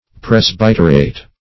presbyterate - definition of presbyterate - synonyms, pronunciation, spelling from Free Dictionary
Search Result for " presbyterate" : The Collaborative International Dictionary of English v.0.48: Presbyterate \Pres*byt"er*ate\, n. [L. presbyteratus: cf. F. presbyt['e]rat.]